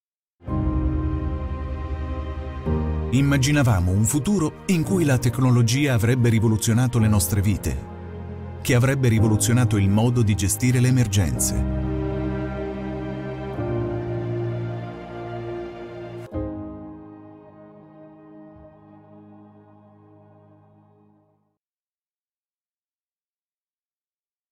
Italiano
Narração
Microfone Neumann TLM 103